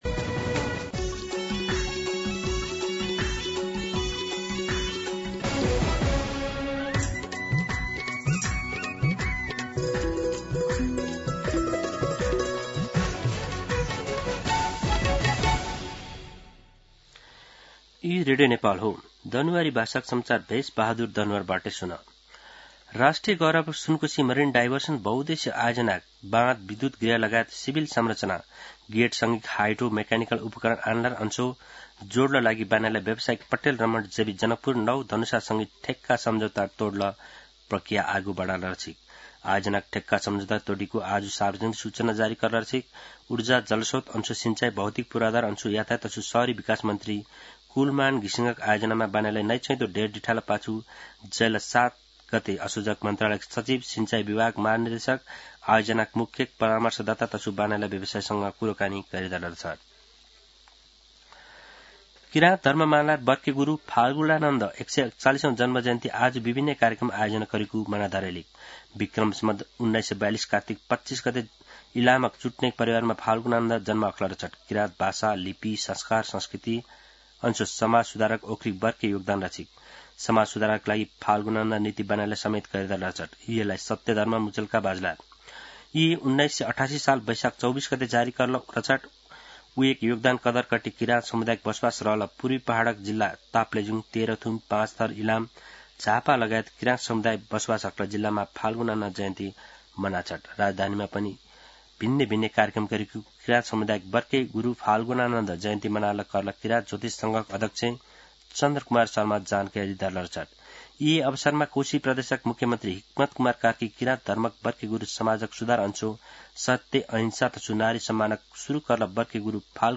दनुवार भाषामा समाचार : २५ कार्तिक , २०८२
Danuwar-News-07-25.mp3